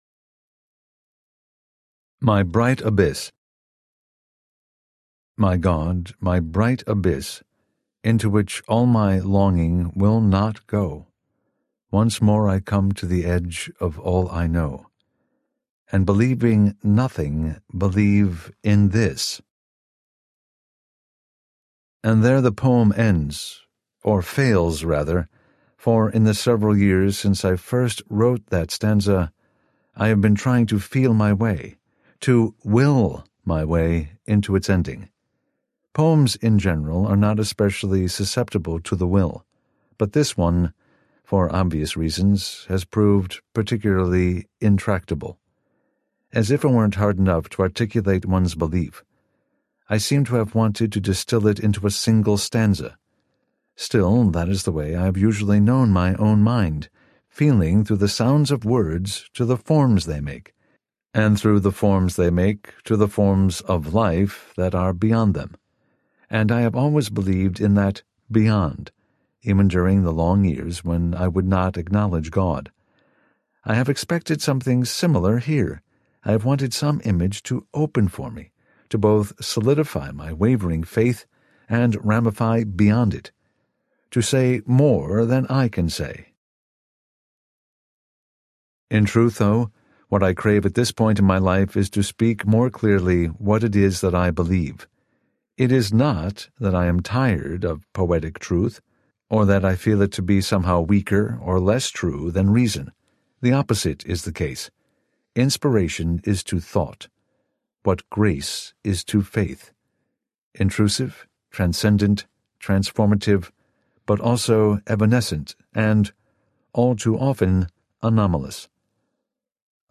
My Bright Abyss Audiobook